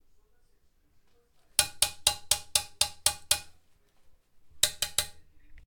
Golpeo con instrumento de plástico o metal
Grabación sonora del sonido del golpeo de un instrumento de páltico o metal (una varilla, cuchara o similar) al golpear contra una superficie.
Sonidos: Acciones humanas